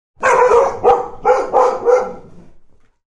Звуки собак
Две собаки облаивают незнакомца